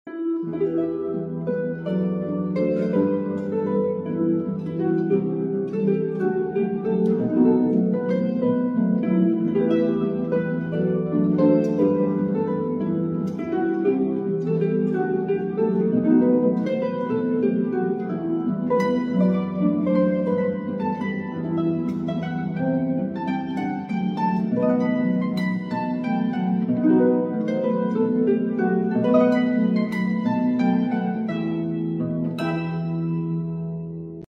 Harp cover